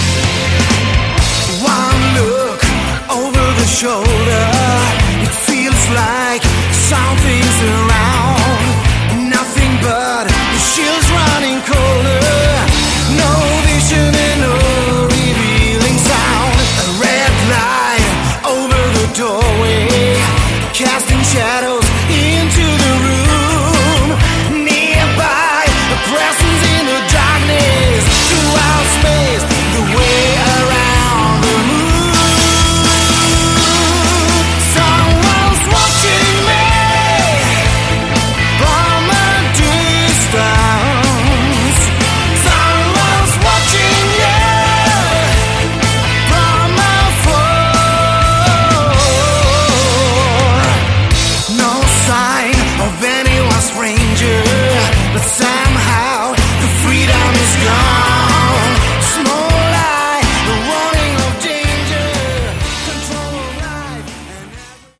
Category: Melodic Hard Rock